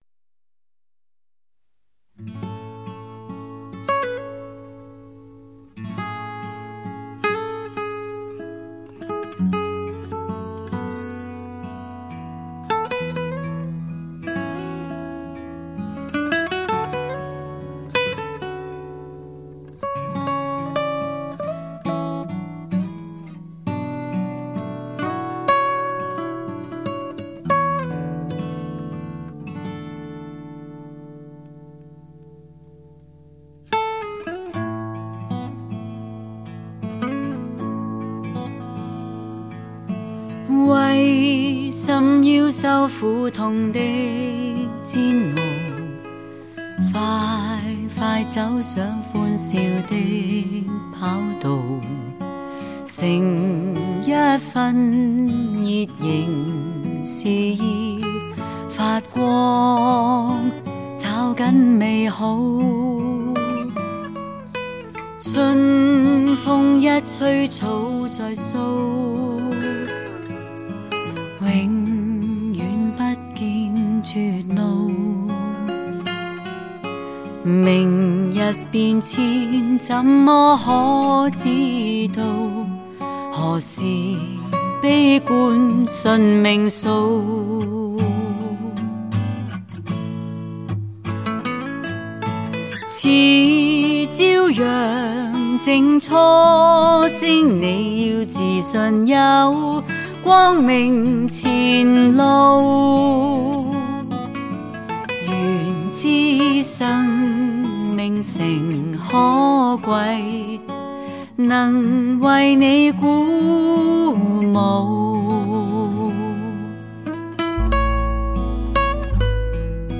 24Bit 96KHz High Density Audiophile Mastering
高解像发烧录制 靓声演绎段段情歌